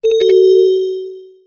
玄関のチャイム音。
低音のチャイム音 着信音